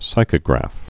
(sīkə-grăf)